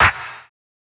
SNARE_SLIT.wav